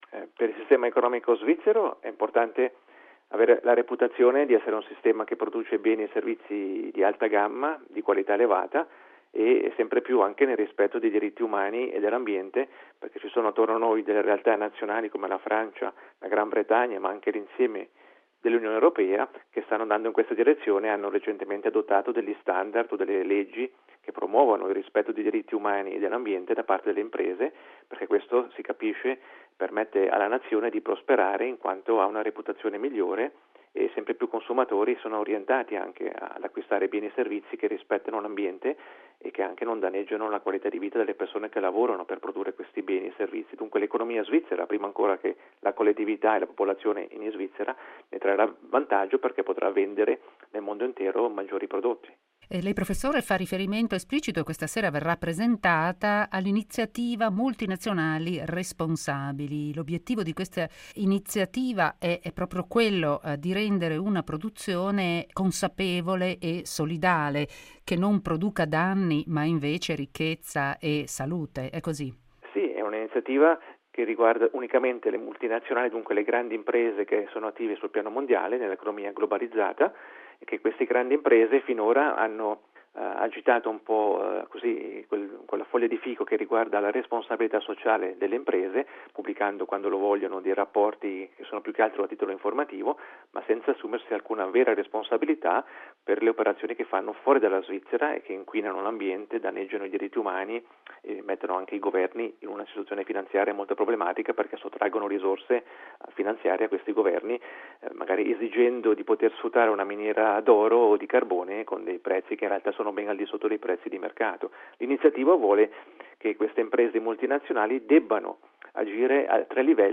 Occasione anche per la presentazione dell’Iniziativa Multinazionali responsabili. Abbiamo intervistato l’economista.